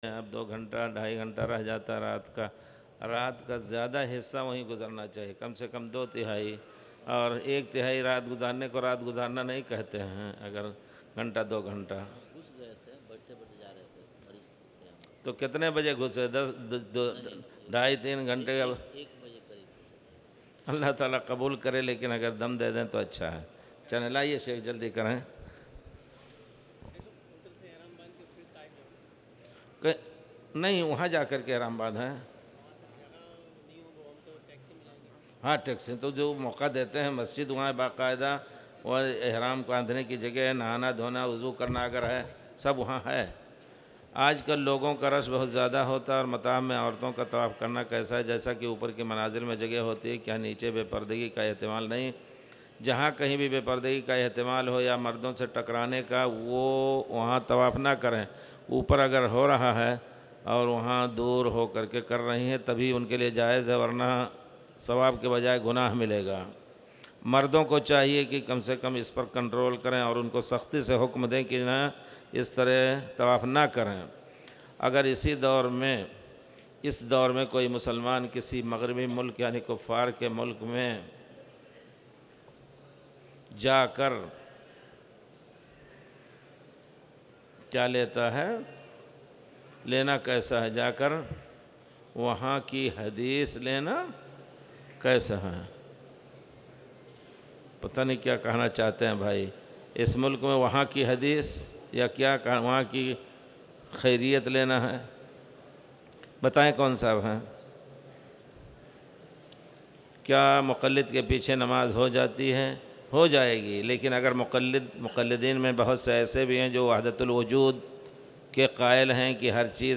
تاريخ النشر ١٨ ذو الحجة ١٤٣٨ هـ المكان: المسجد الحرام الشيخ